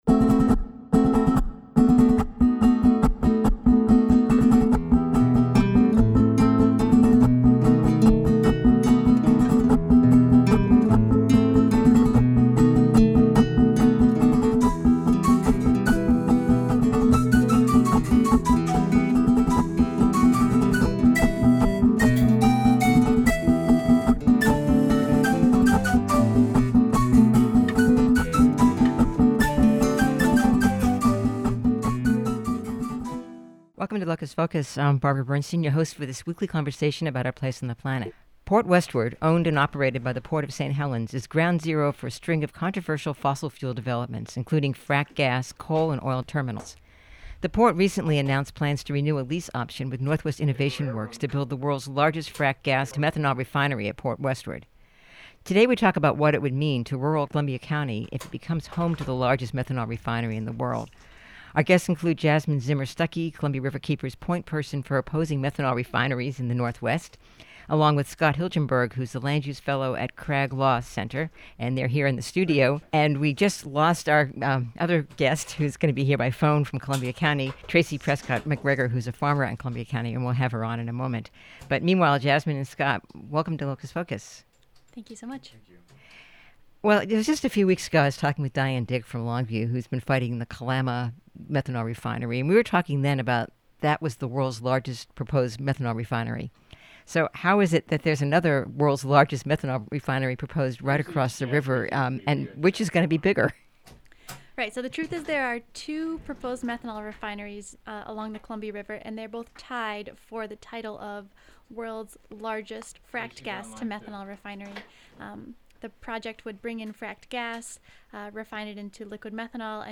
On this episode of Locus Focus we talk about what it would mean to rural Columbia County if it becomes home to the largest methanol refinery in the world, with guests